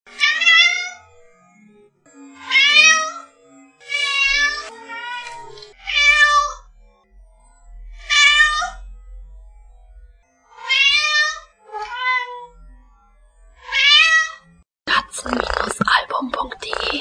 katzen-album_katzen_betteln_fressen.mp3